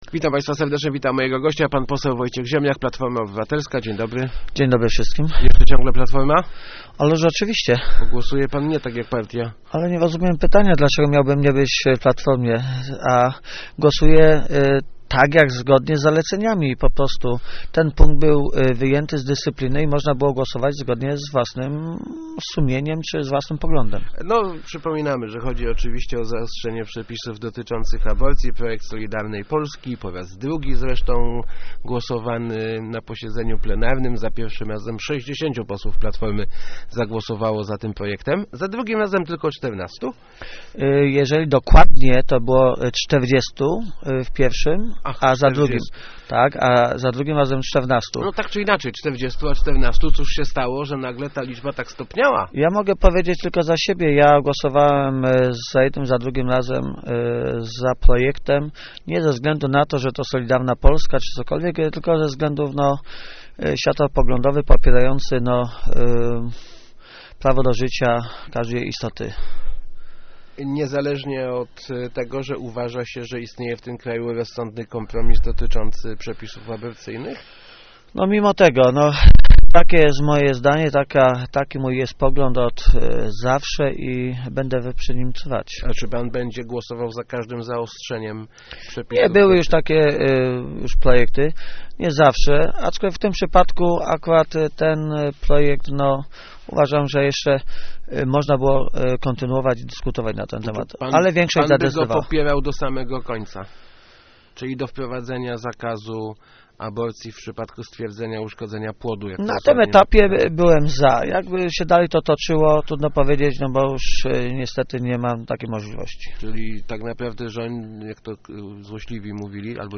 wojziem80.jpgNie było żadnych nacisków, ani od premiera, ani od biskupów - mówił w Rozmowach Elki poseł PO Wojciech Ziemniak, komentując swoją decyzję w sprawie zaostrzenia ustawy aborcyjnej. Ziemniak był jednym z 14 posłów Platformy, którzy poparli projekt Solidarnej Polski.